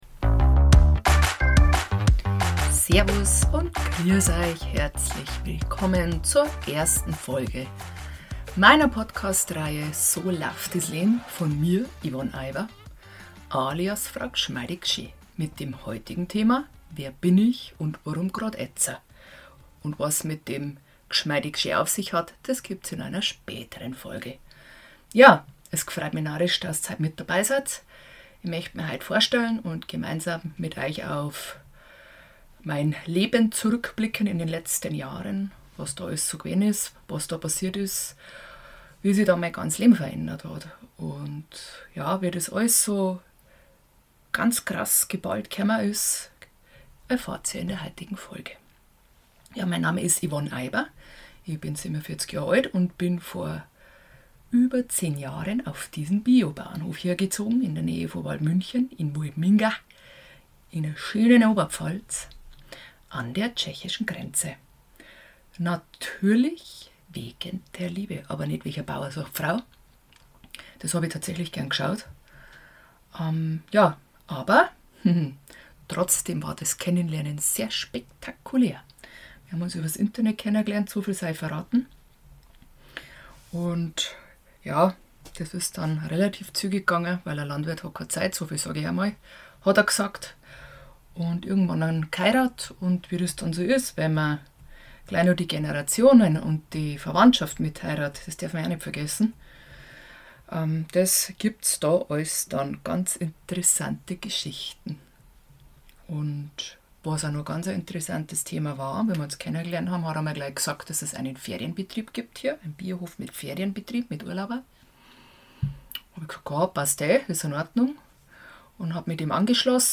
BAYERISCH PRAKTISCH GUAD GRODAUS, So laft des Lem
Comedy , Gesellschaft & Kultur